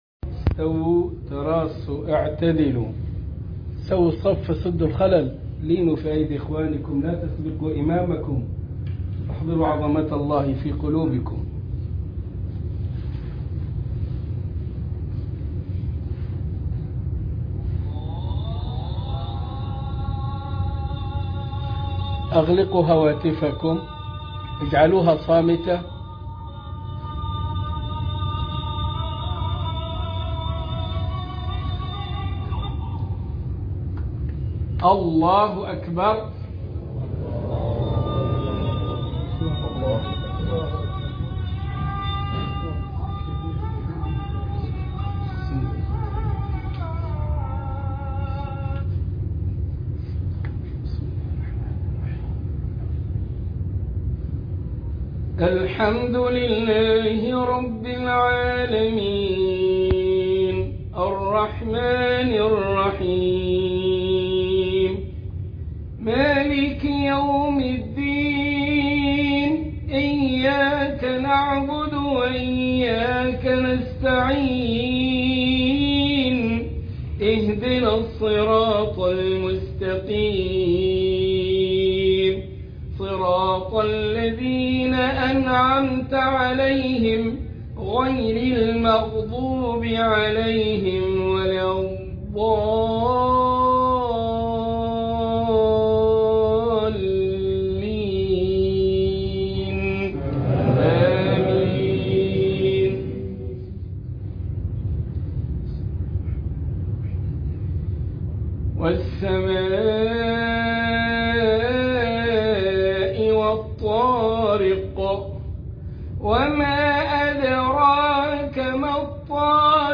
خاطرة المغرب من المركز الإسلامي بمدينة مونتريالكندا